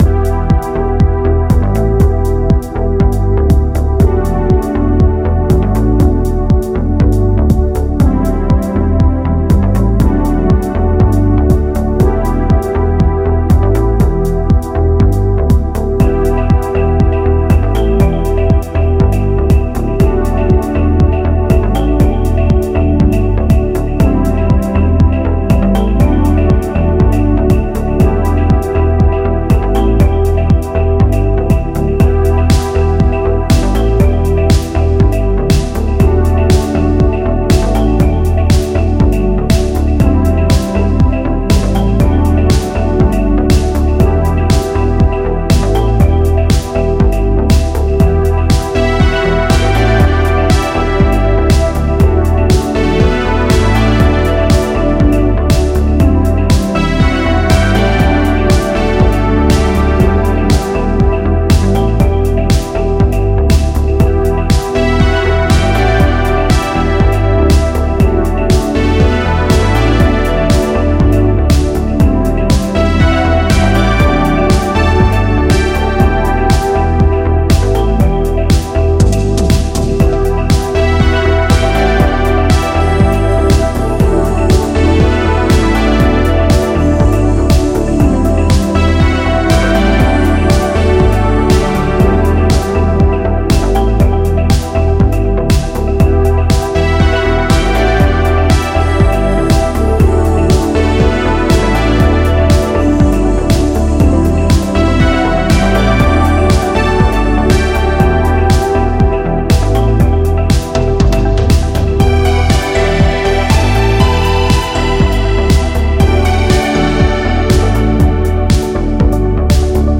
Жанр: Synthwave, Retrowave, Spacewave, Dreamwave, Electronic